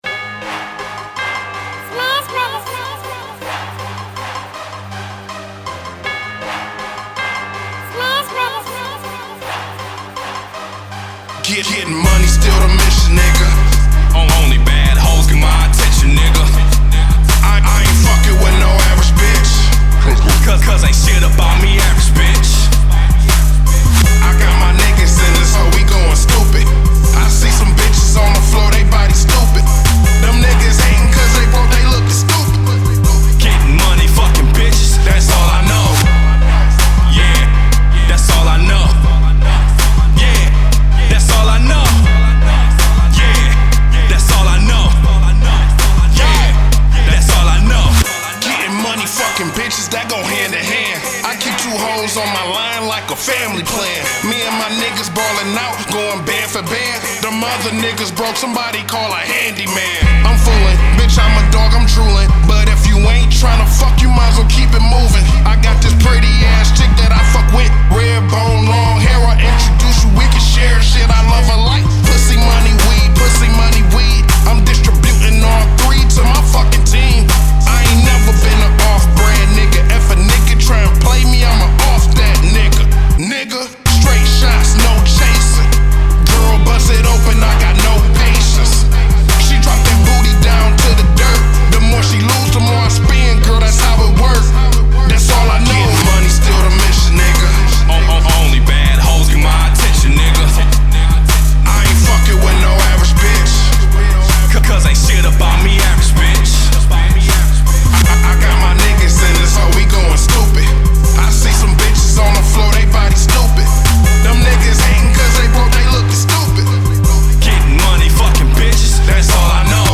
Another club BANGER